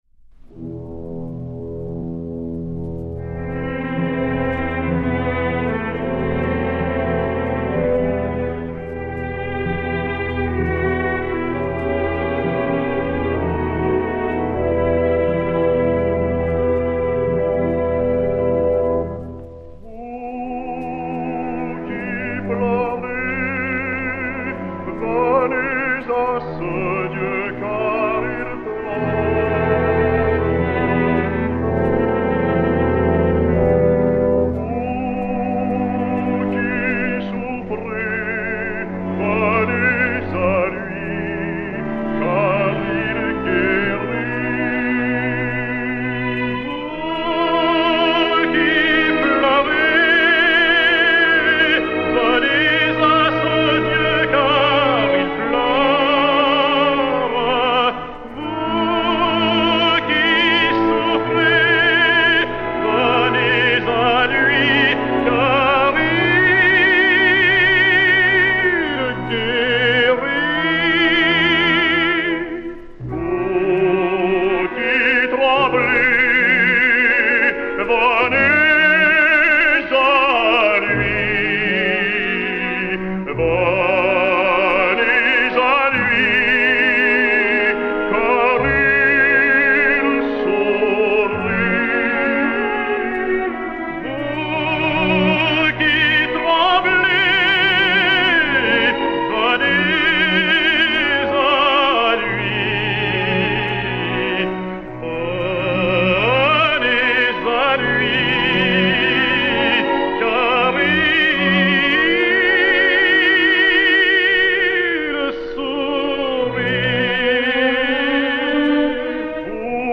Chant religieux à deux voix